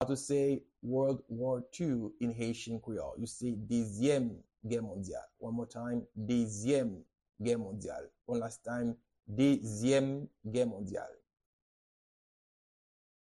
Pronunciation and Transcript:
How-to-say-World-War-2-in-Haitian-Creole-Dezyem-Ge-Mondyal-pronunciation.mp3